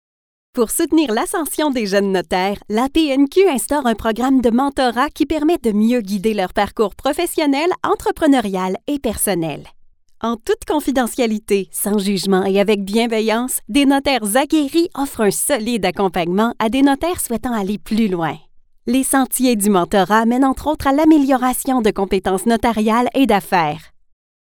Französisch (Kanada)
Natürlich, Vielseitig, Freundlich, Zugänglich, Zuverlässig
Unternehmensvideo